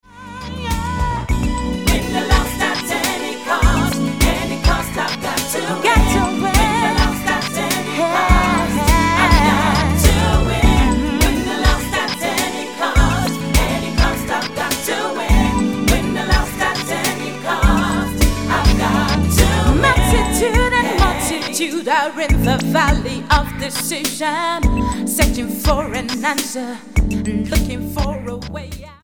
London-based gospel singer
Style: Gospel